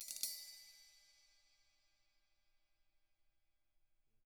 Index of /90_sSampleCDs/ILIO - Double Platinum Drums 2/Partition H/CYMBALRUFFSD